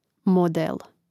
mòdel model